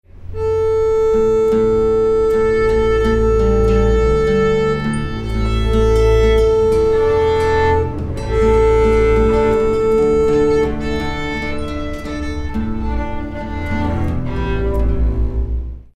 Afinación de varios instrumentos antes de un concierto
afinar
instrumento